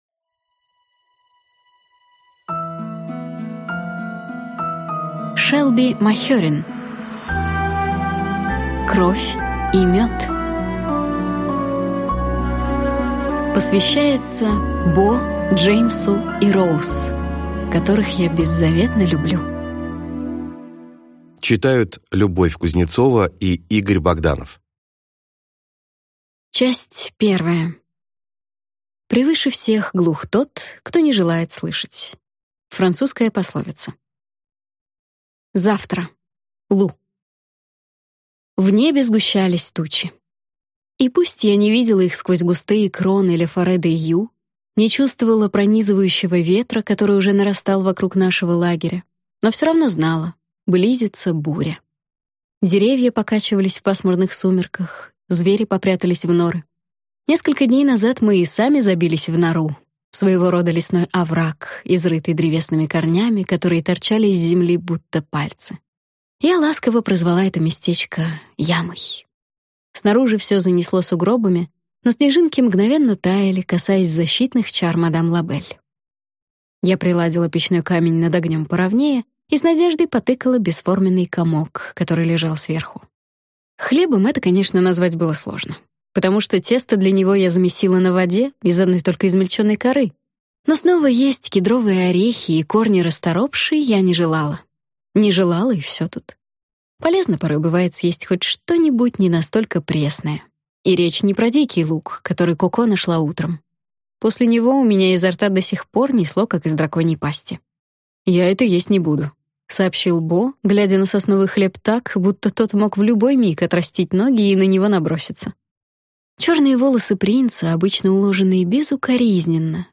Аудиокнига Кровь и мёд | Библиотека аудиокниг